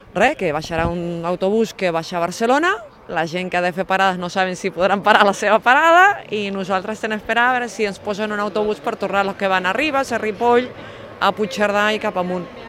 Una passatgera afectada per l’incendi a l’R3: “Hem sentit olor de cremat i hem baixat pitant del tren” ( Àudio 4 )